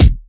kick 32.wav